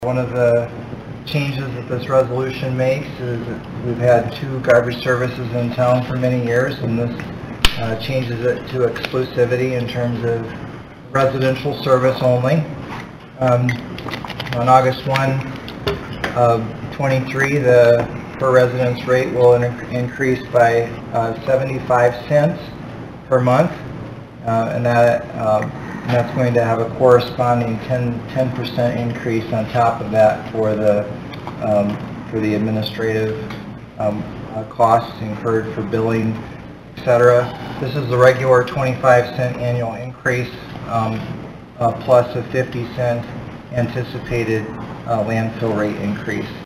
Sheldon City Manager Sam Kooiker explained the changes in the new garbage hauling contract to the Council during Wednesday’s meeting.